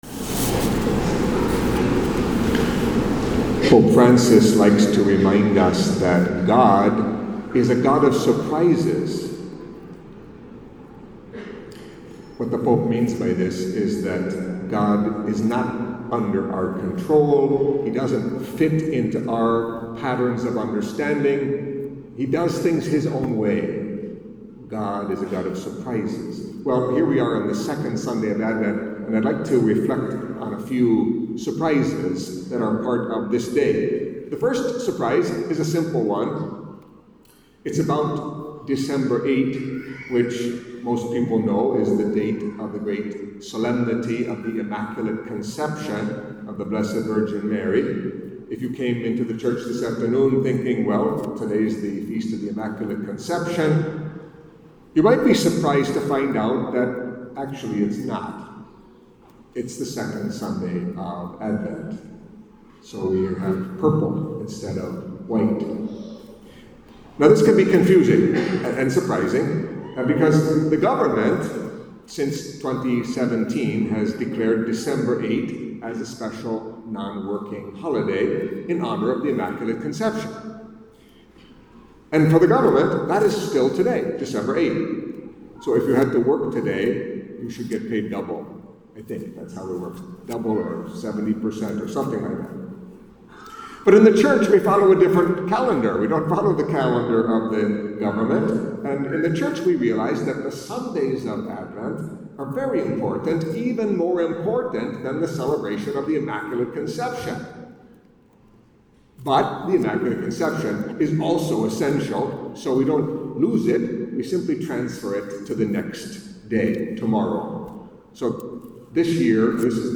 Catholic Mass homily for the Second Sunday of Advent